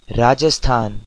North Indian pronunciation of Rajasthan
Volume Boosted Using Audacity